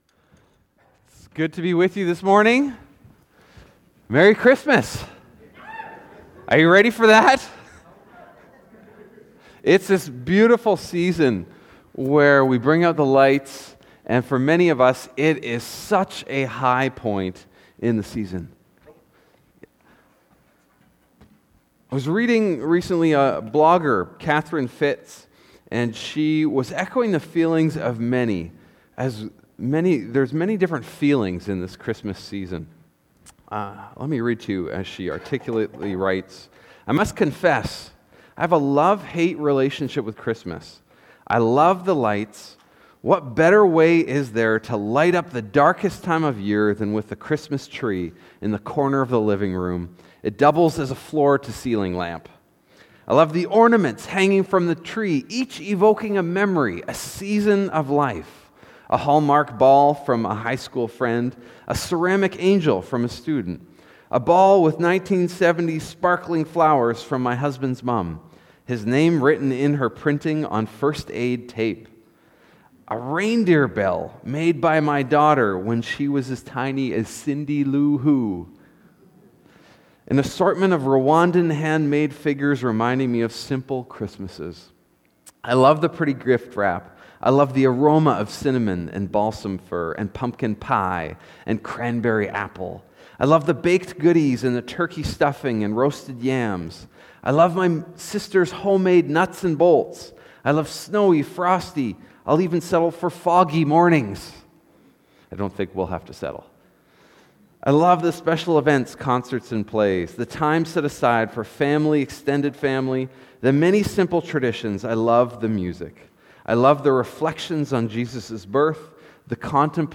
Sermons | Northstar Church
Guest Speaker